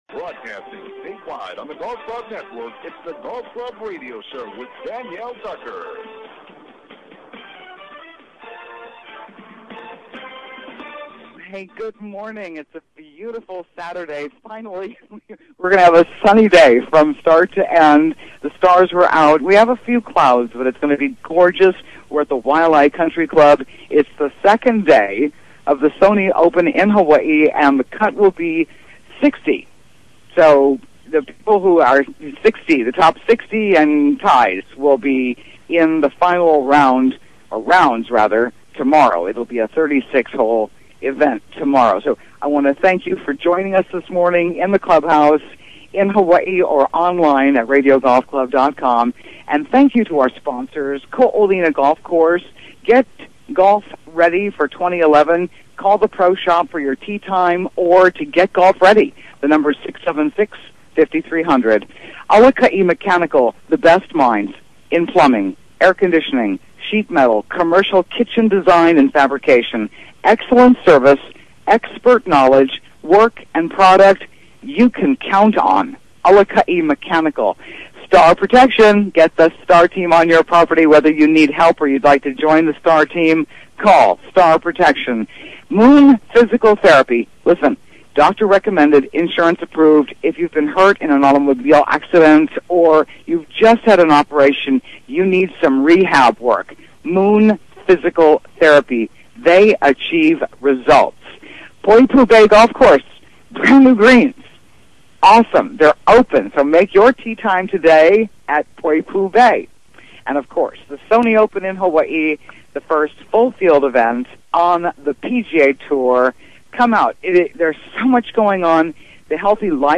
Live from The Sony Open at The Waialae Country Club